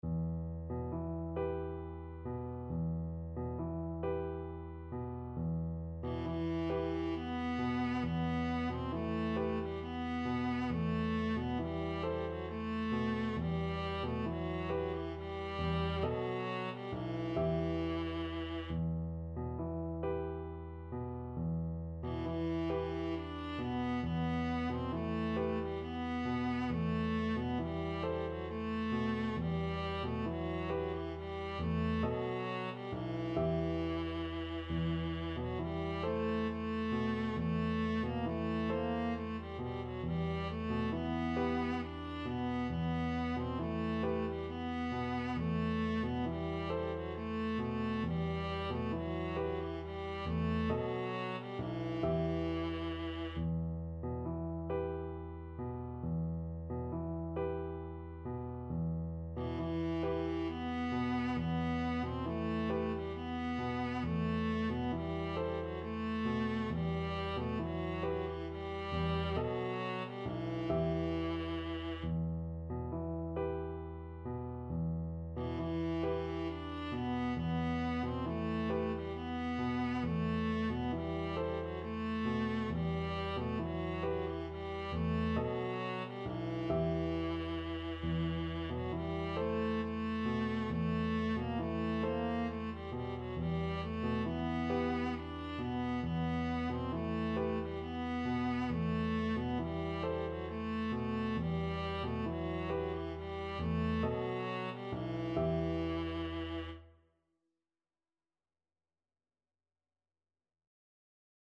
Viola
6/8 (View more 6/8 Music)
E minor (Sounding Pitch) (View more E minor Music for Viola )
Gently rocking .=c.45
Turkish